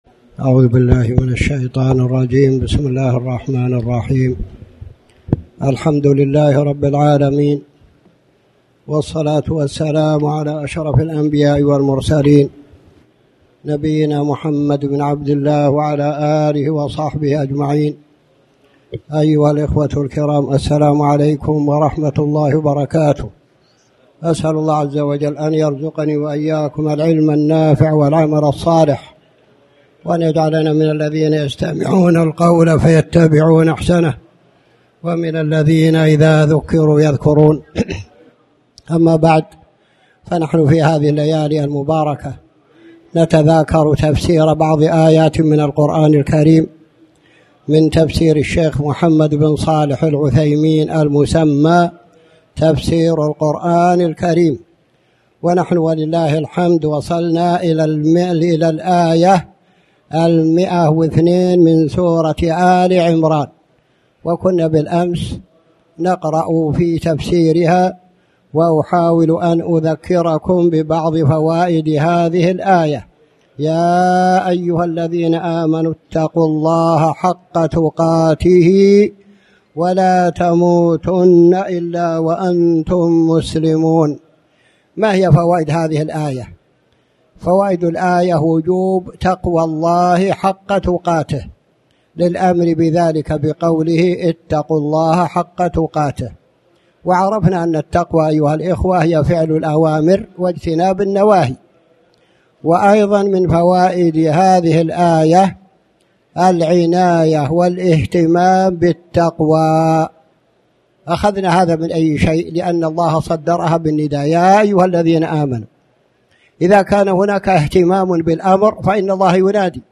تاريخ النشر ١٧ ربيع الأول ١٤٣٩ هـ المكان: المسجد الحرام الشيخ